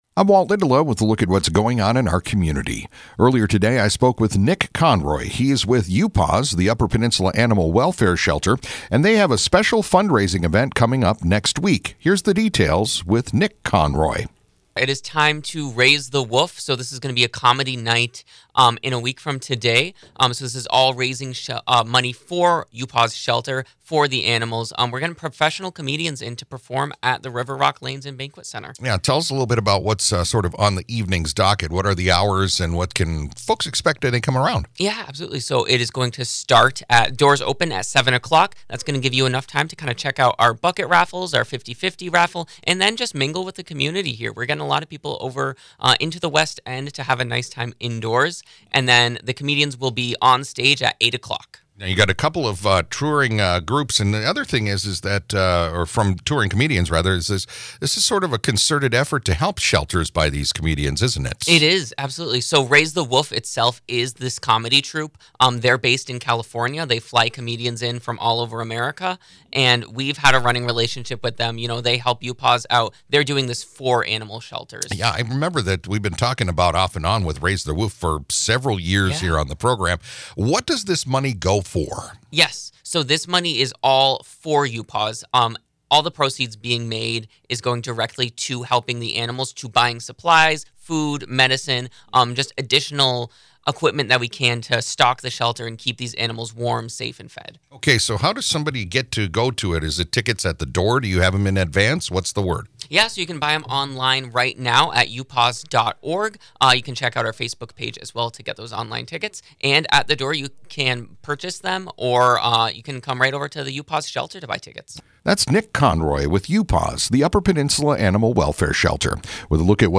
spoke about the evening